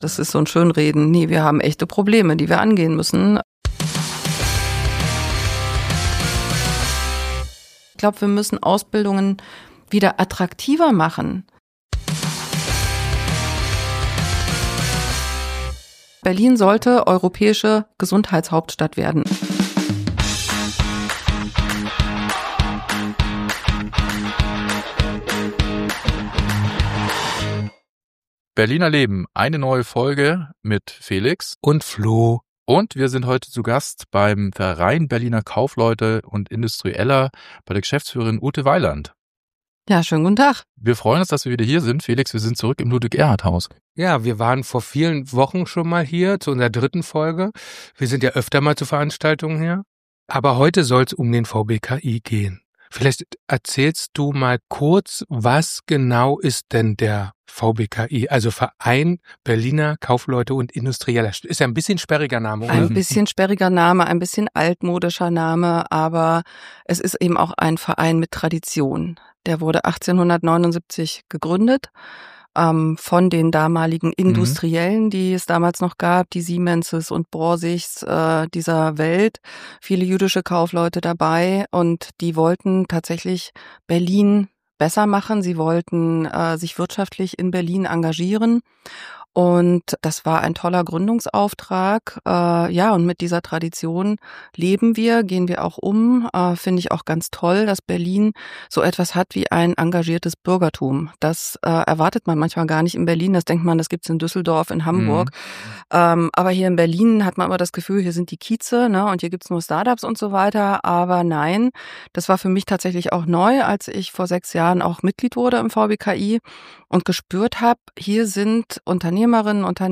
Ein interessantes und inspirierendes Gespräch über Verantwortung, Chancen und die Zukunft der Hauptstadt.